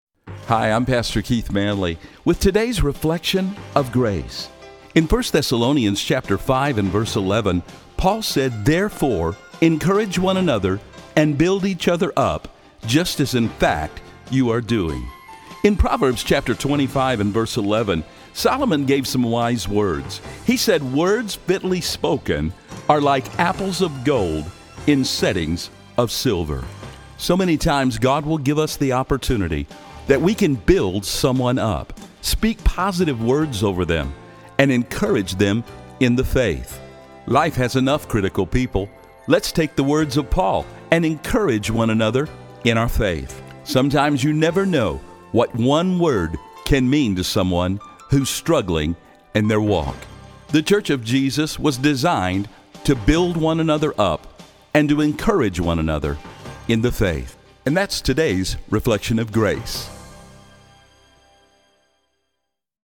These spots air locally on 93.3 FM and on the Wilkins Radio Network heard in 27 Radio Stations around the country.